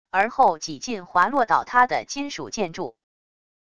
而后几近滑落倒塌的金属建筑wav音频